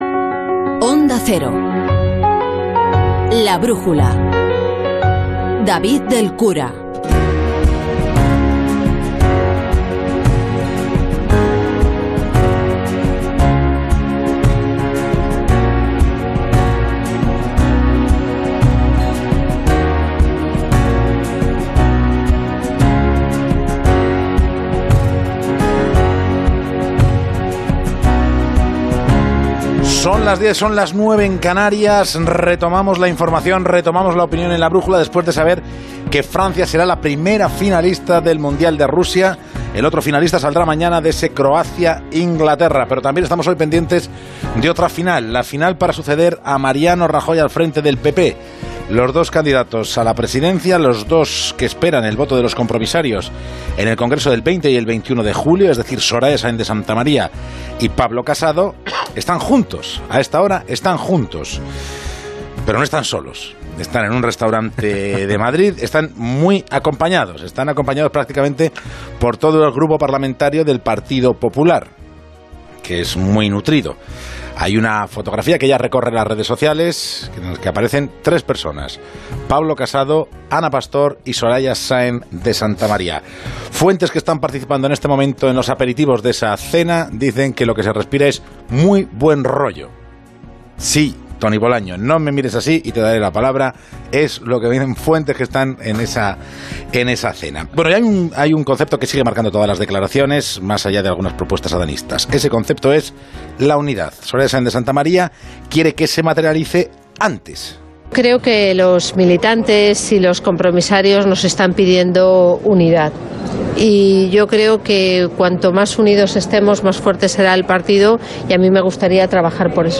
La tertulia de actualidad: La ruta del independentismo